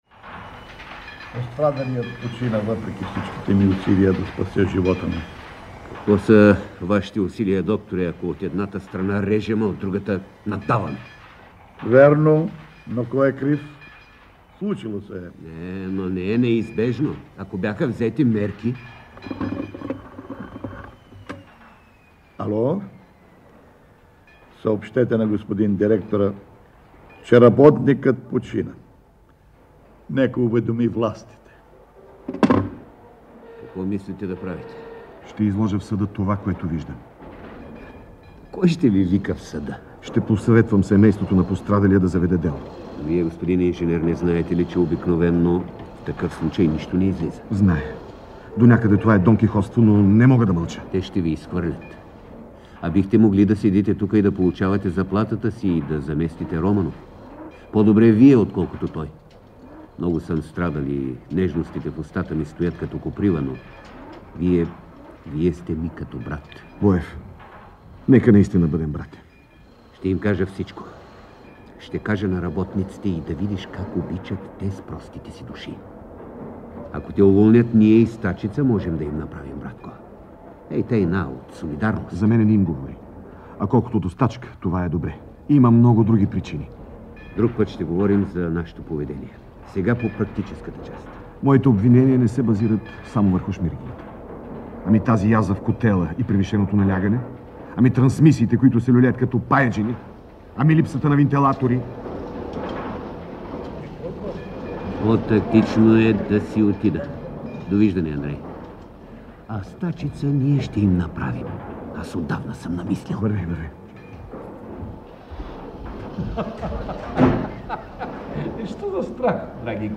В откъс от пиесата, записана в БНР, участват Стефан Данаилов, Георги Новаков и Сотир Майноловски:
Devetata-valna_radiopiesa-ot-Vaptsarov.mp3